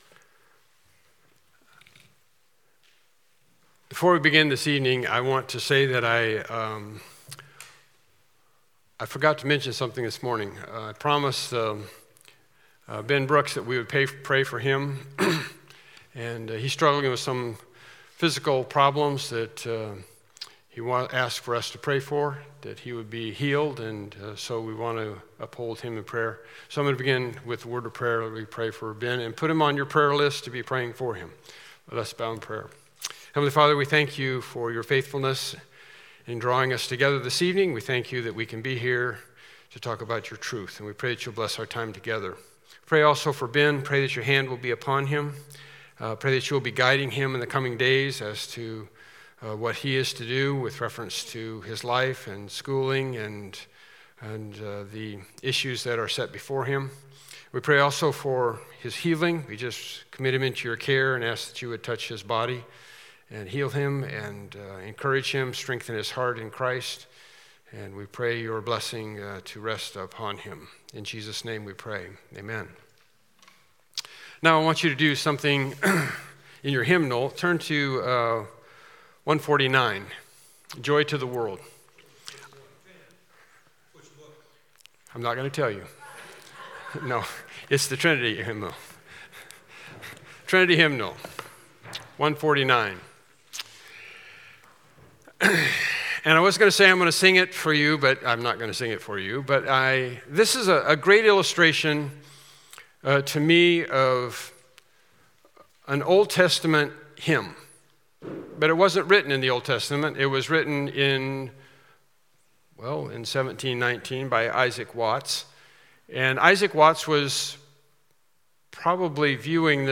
Various Service Type: Evening Worship Service « Pursuing Humility 2019 Christmas Service Part 1